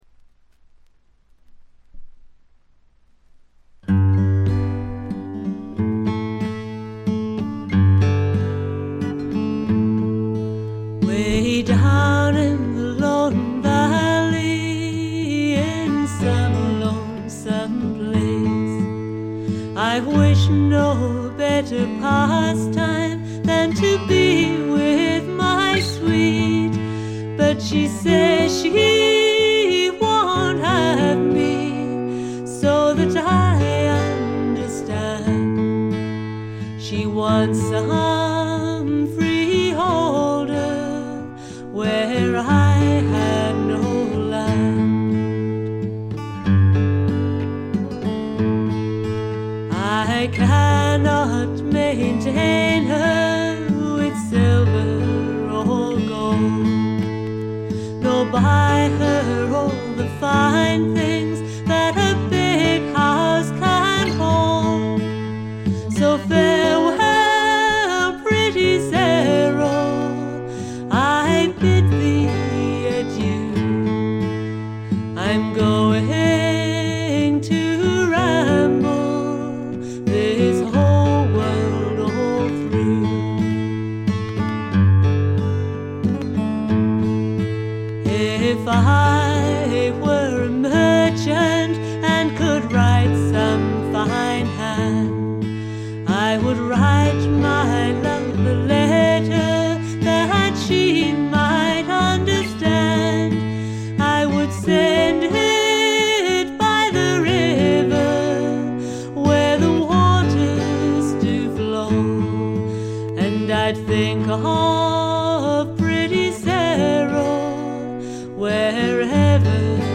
試聴曲は現品からの取り込み音源です。
Vocals
Guitar, Flute